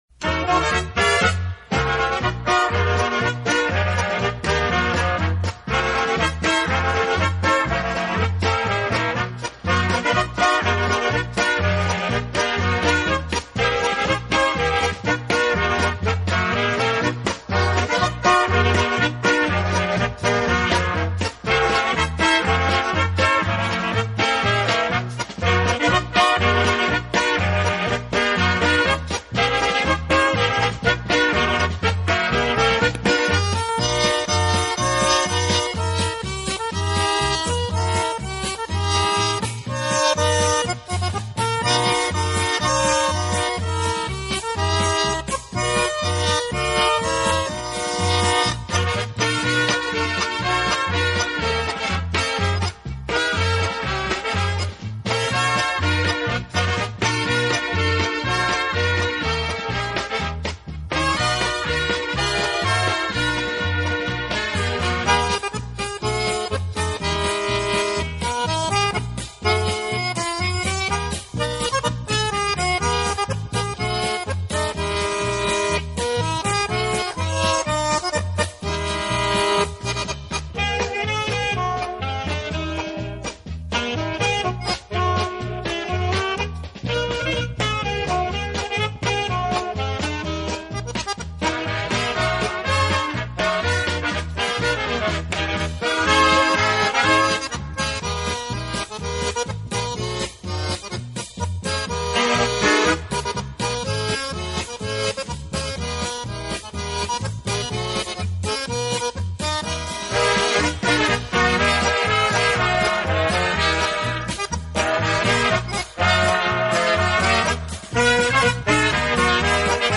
Commentary 8.